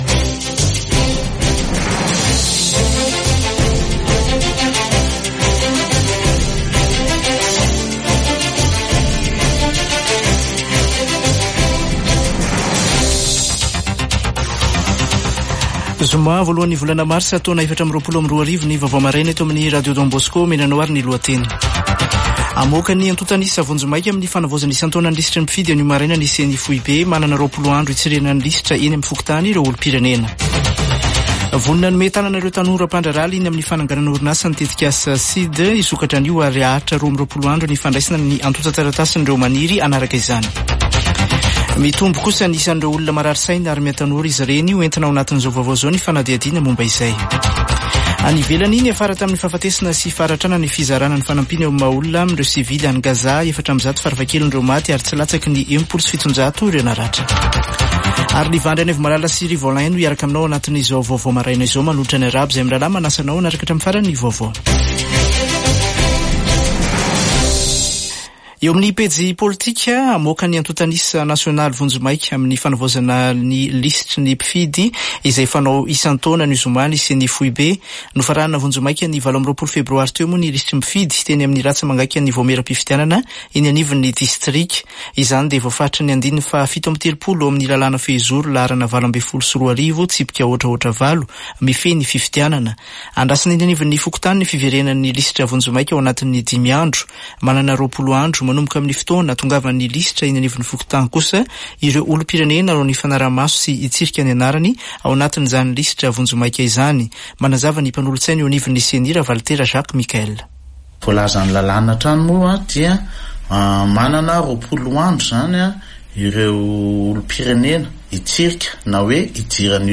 [Vaovao maraina] Zoma 1 marsa 2024